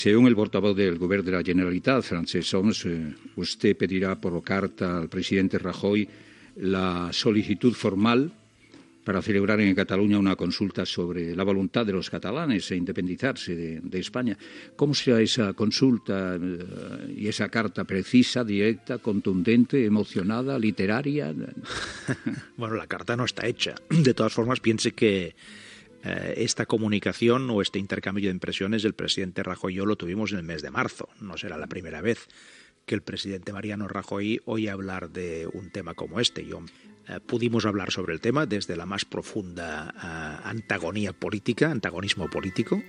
Fragment d'una entrevista al president de la Generalitat Artur Mas sobre la consulta relacionada amb la independència de Catalunya
Info-entreteniment